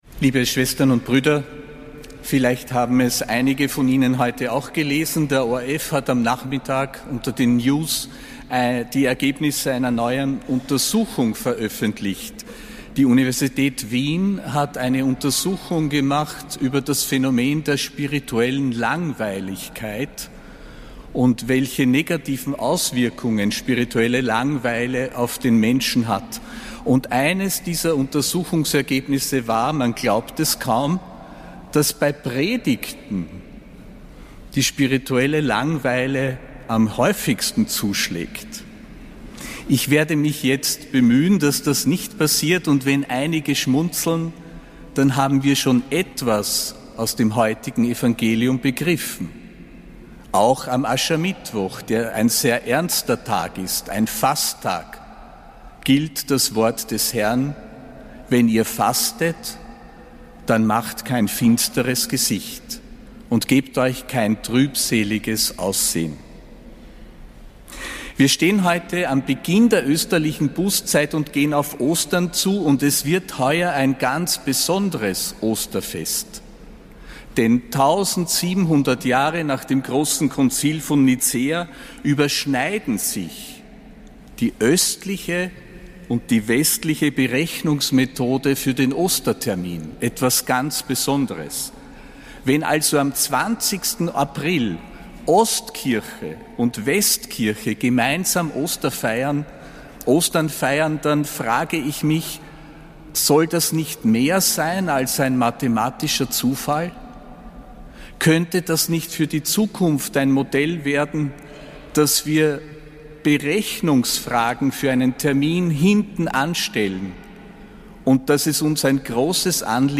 Predigt des Apostolischen Administrators Josef Grünwidl zum Aschermittwoch, 5. März 2025.